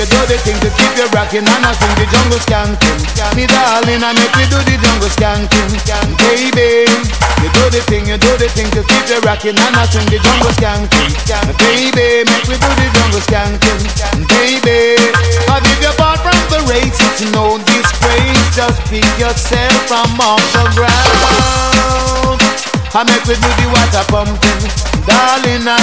TOP >Vinyl >Drum & Bass / Jungle
TOP > Vocal Track